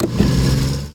car-engine-load-reverse-4.ogg